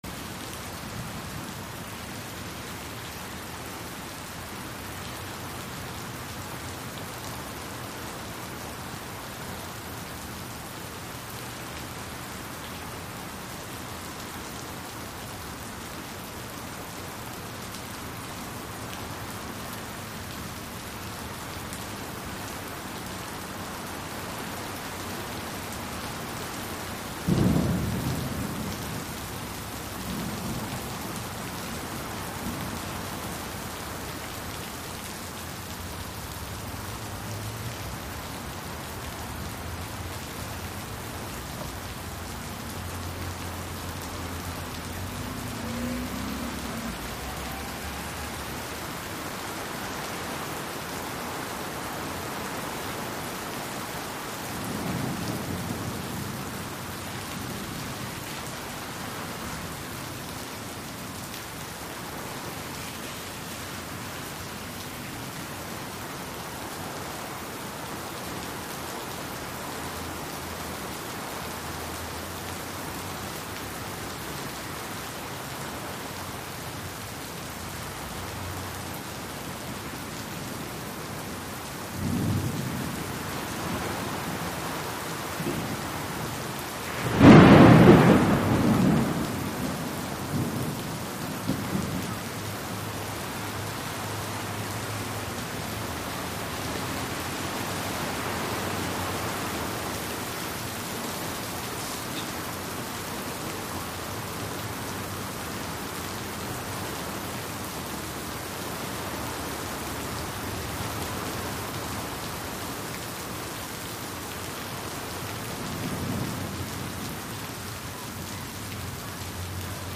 Thunder, Rain | Sneak On The Lot
Rain; Medium To Heavy Rain With Some Thunder And Distant Traffic Wash.